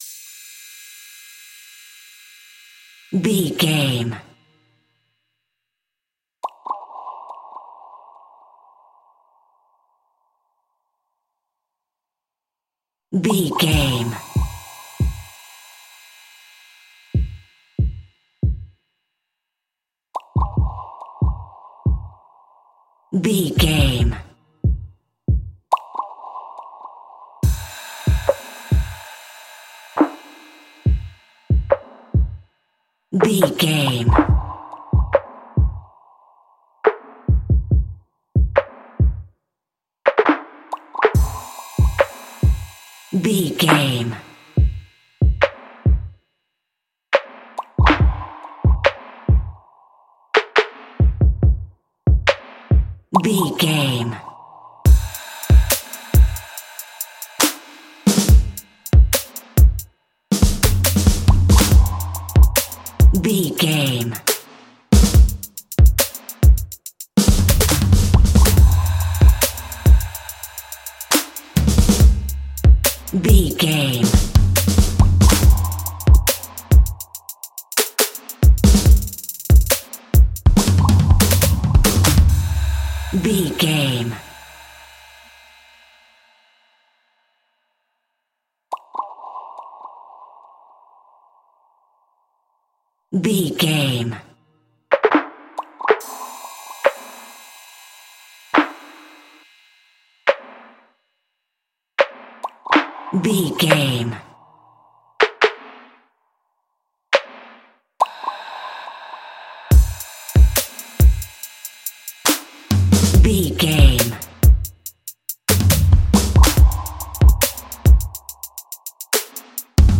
Aeolian/Minor
Fast
driving
bouncy
energetic
drum machine
percussion